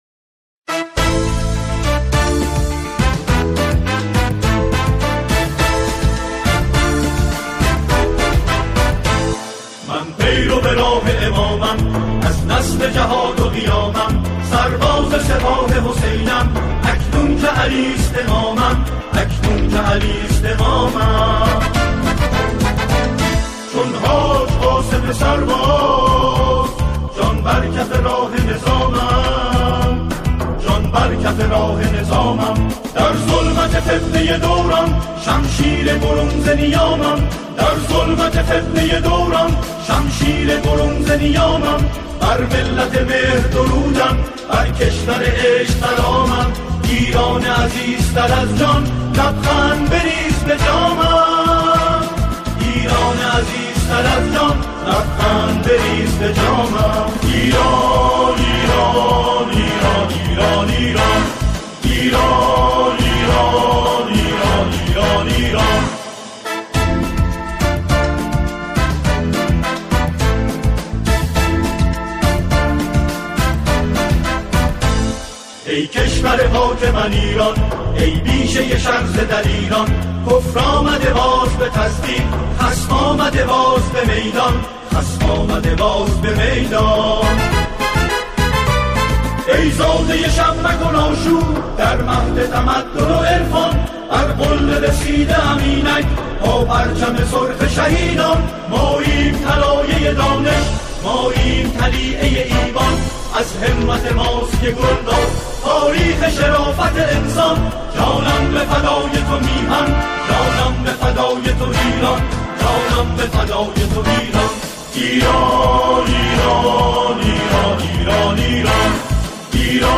با اجرای گروهی از جمعخوانان